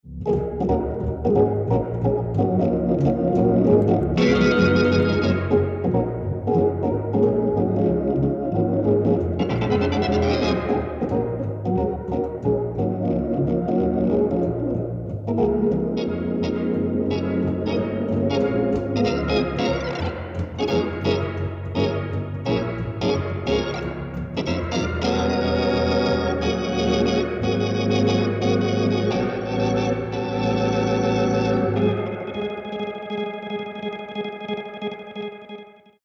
jazz pianist/organist